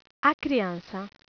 In this page, you can hear some brazilian portuguese words/phrases.
The child... - note the 'ç'... it sounds exactly like 'ss'.